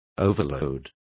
Complete with pronunciation of the translation of overloads.